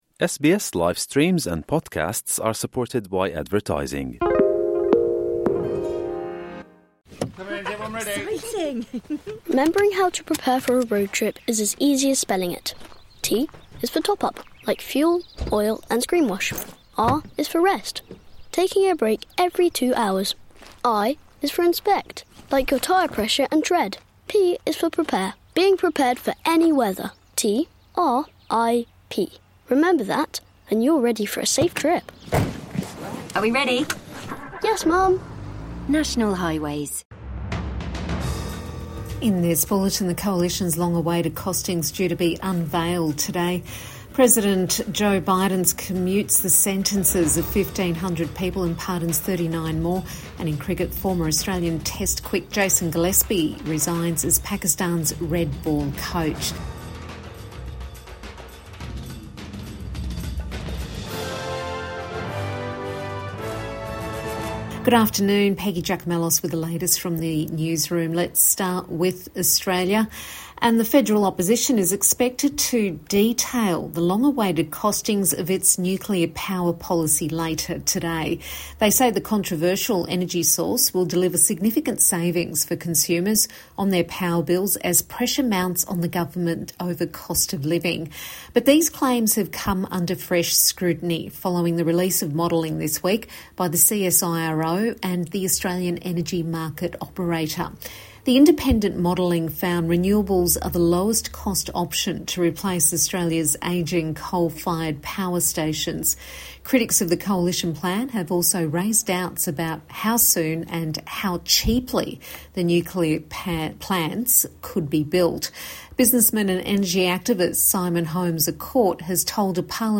Midday News Bulletin 13 December 2024